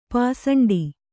♪ pāsaṇḍi